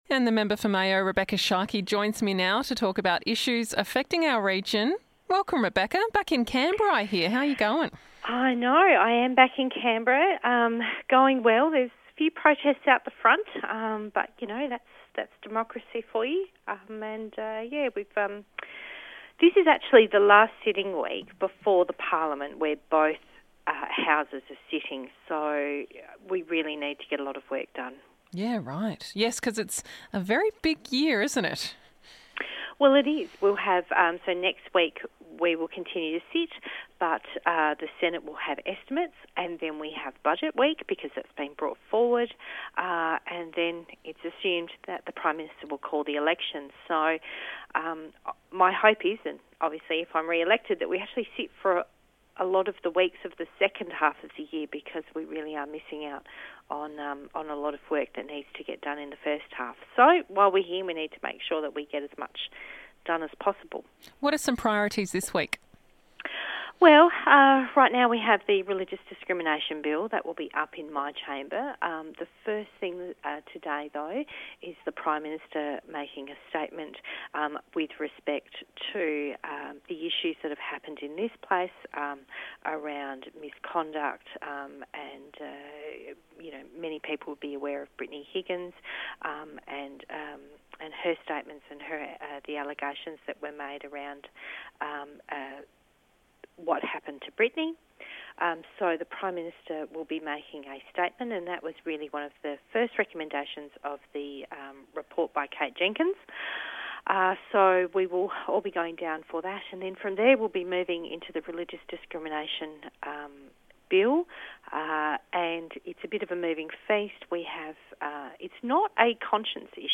Rebekha Sharkie Calls from Canberra